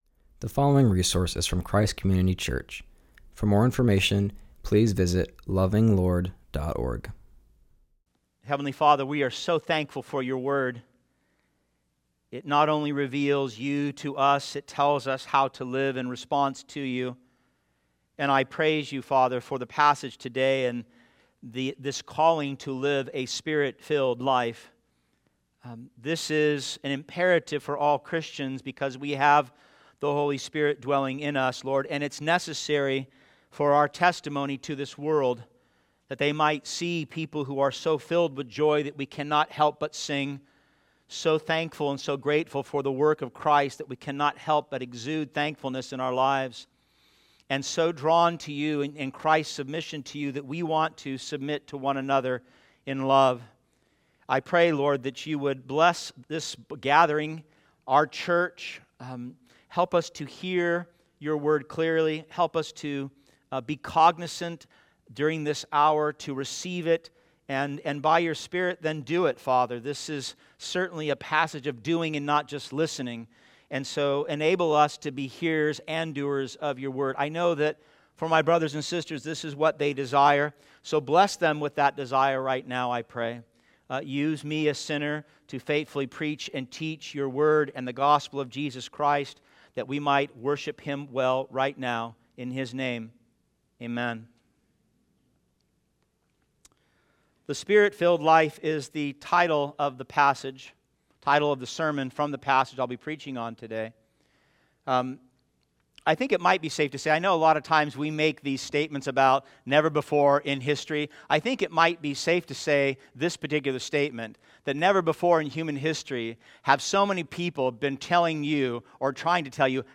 preaches from Ephesians 5:18-21